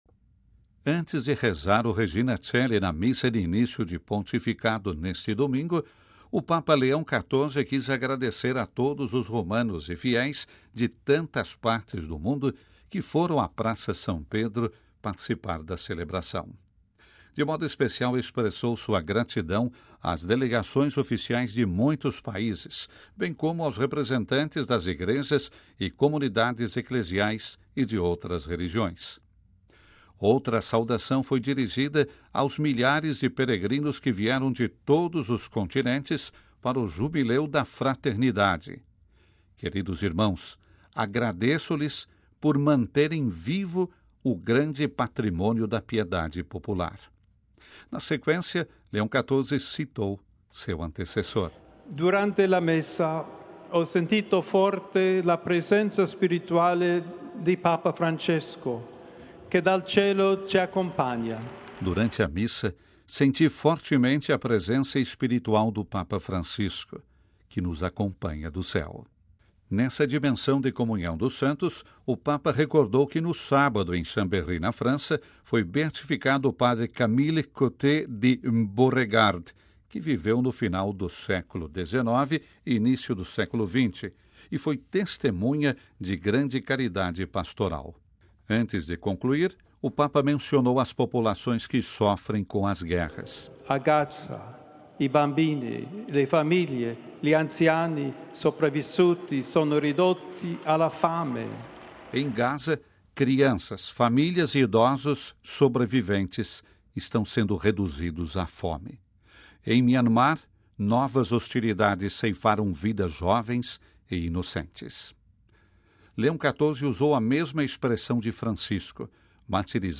Antes de rezar o Regina Caeli na missa de início de pontificado,  o Papa Leão XIV quis agradecer a todos os romanos e fiéis de tantas partes do mundo que foram à Praça São Pedro participar da celebração.